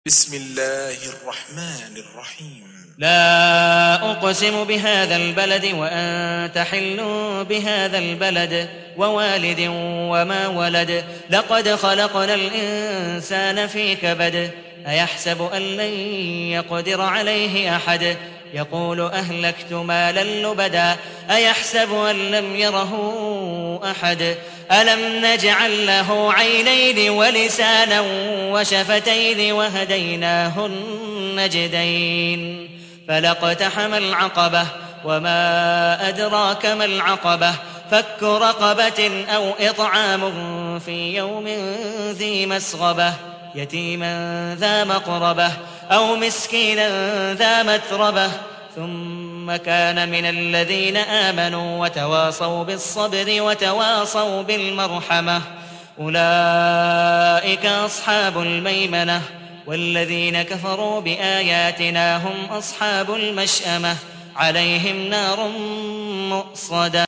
تحميل : 90. سورة البلد / القارئ عبد الودود مقبول حنيف / القرآن الكريم / موقع يا حسين